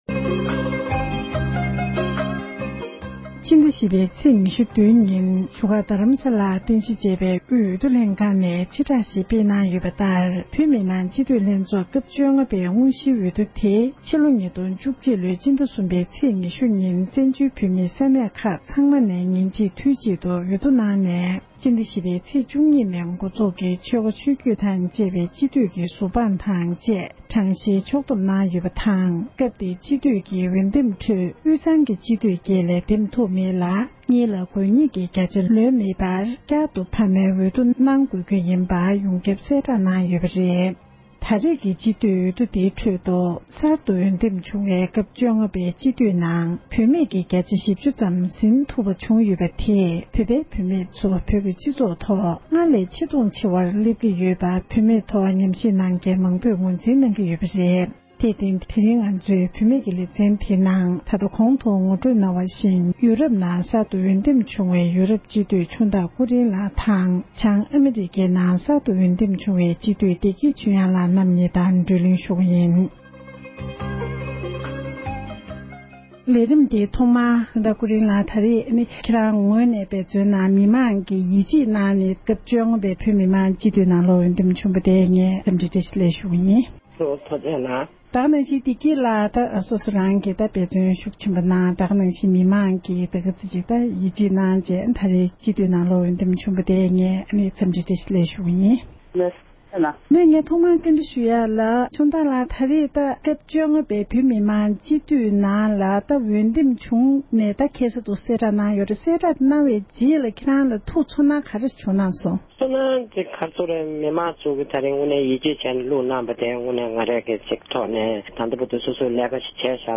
ཐད་ཀར་ཞལ་པར་བརྒྱུད་གནས་འདྲི་ཞུས་པ་ཞིག་ལ་གསན་རོགས་ཞུ༎